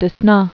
(də-snä, dyə-)